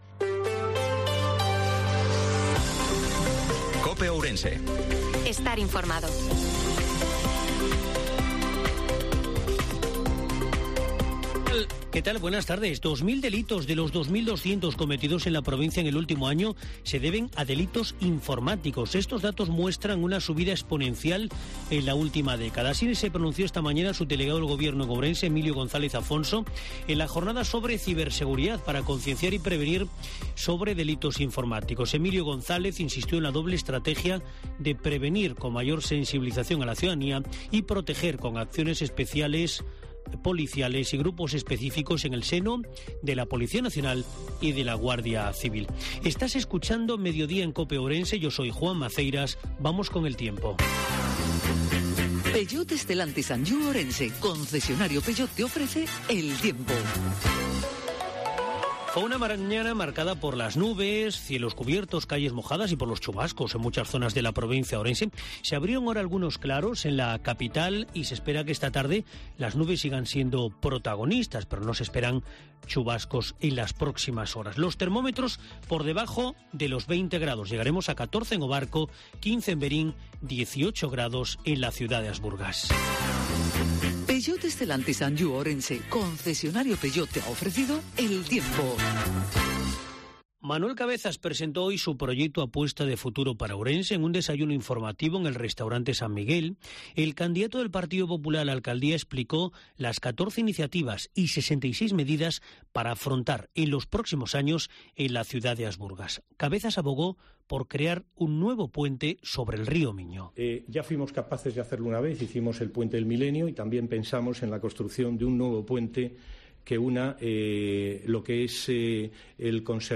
INFORMATIVO MEDIODIA COPE OURENSE-12/03/2023